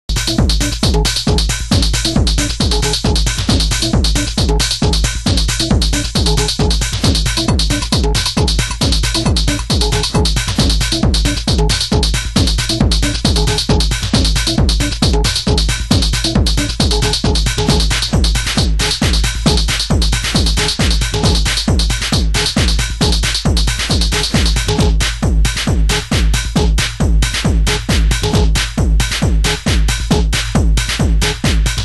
○パワーで押し切るミニマルなシカゴテクノ・アルバム！